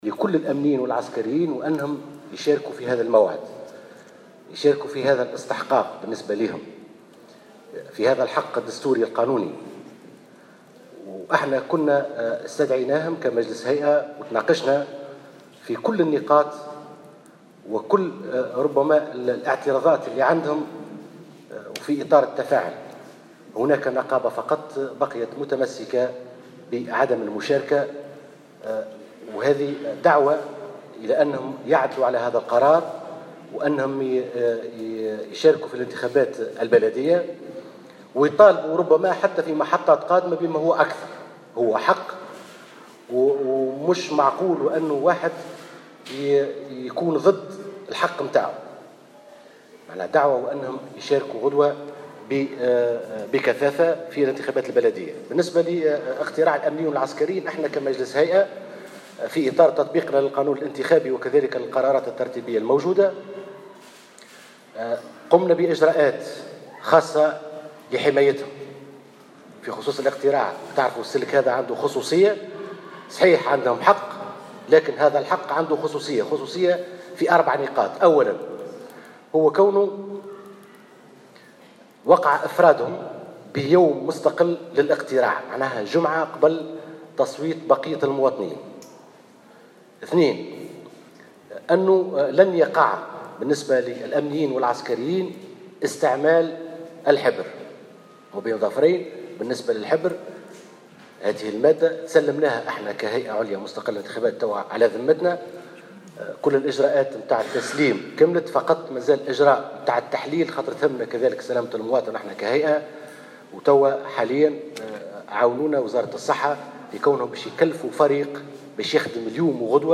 Mansri a rappelé samedi, au micro de Jawhara FM, que quatre mesures exceptionnelles ont été mises en place pour assurer la sécurité de ces électeurs, en ce jour historique qui marque la première participation de ce secteur aux élections.